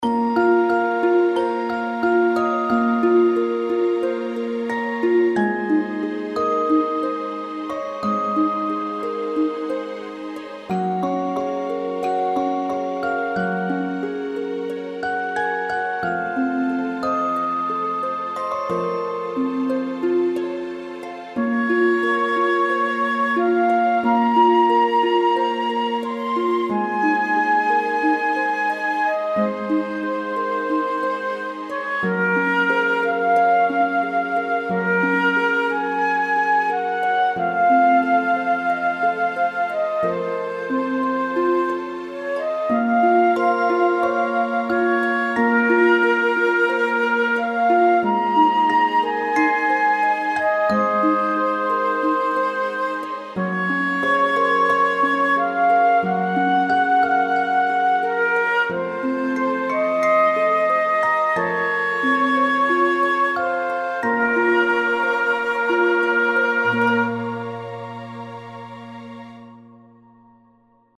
童話樹的白雪公主故事是個有點哀傷的故事，這首音樂搭配得很好。